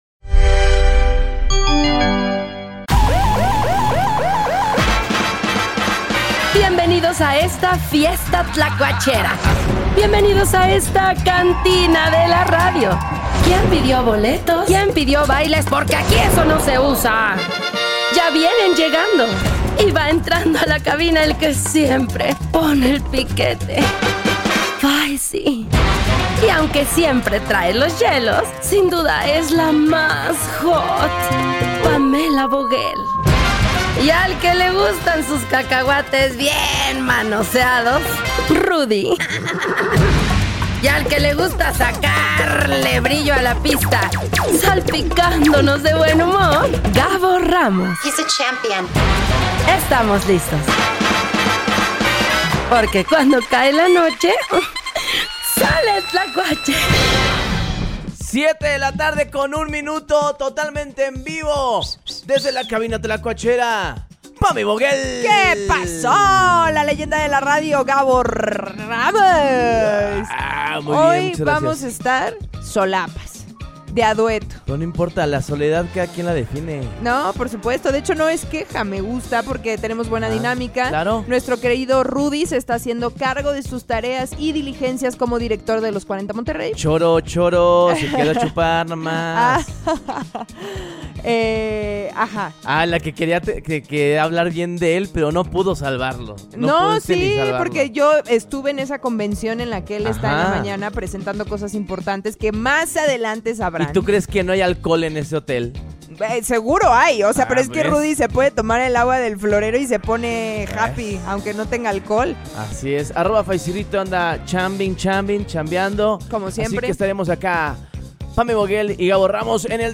Pedro Sampaio en vivo desde Diablopolis…